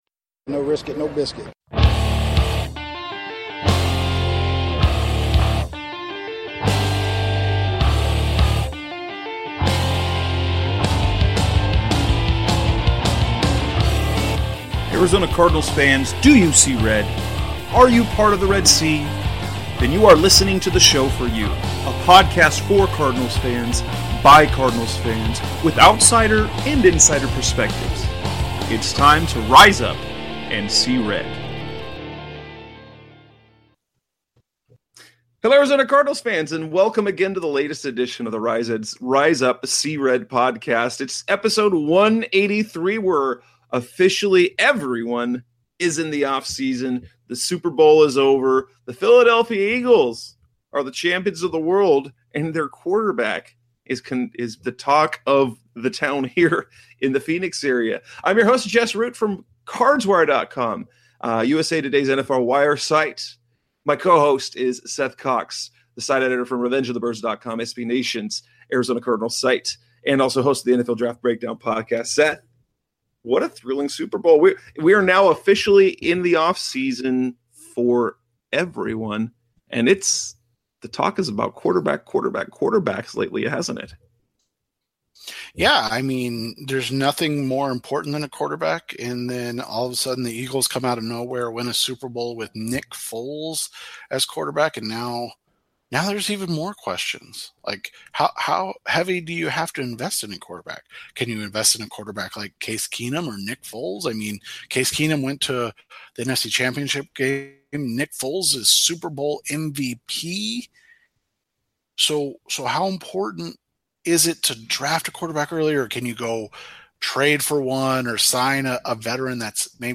Sit back and enjoy the best hour (or more in this case) of Cardinals football talk from a couple of writers who cover and love the team.